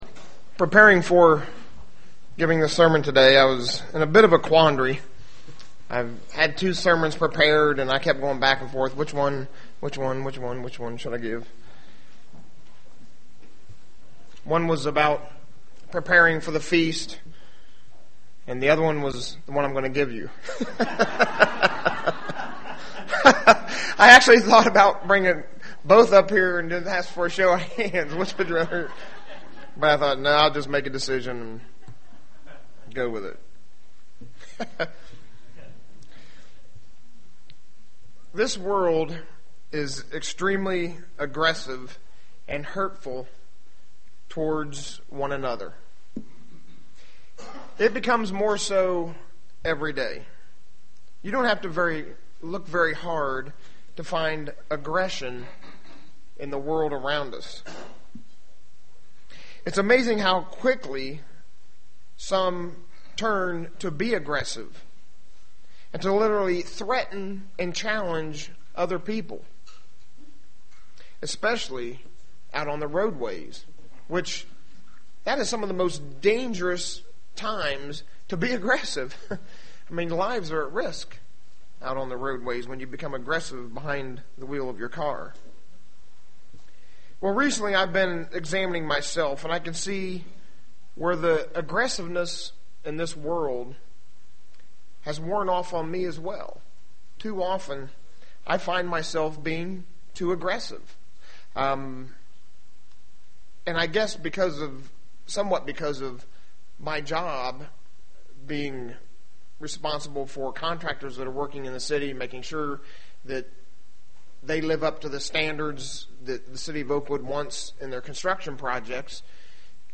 Given in Dayton, OH Ft. Wayne, IN
UCG Sermon Studying the bible?